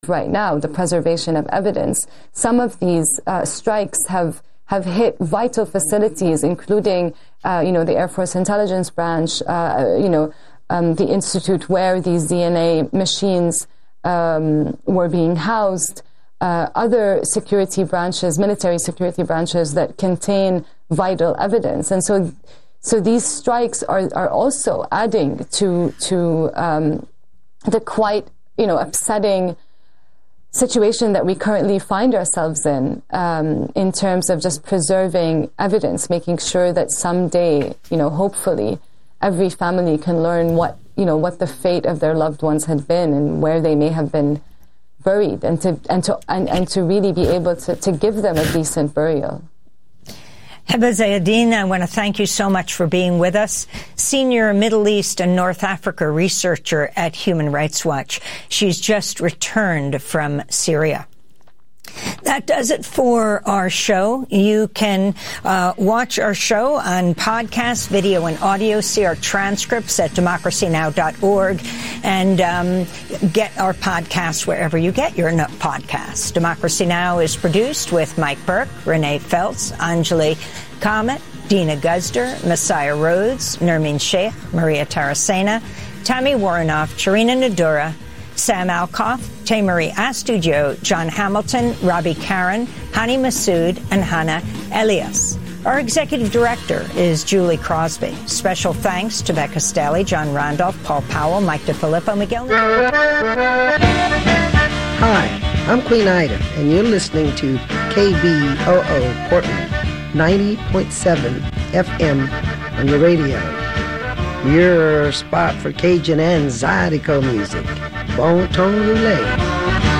OPEN FORUM: KBOO Membership Drive Special Listener Call-In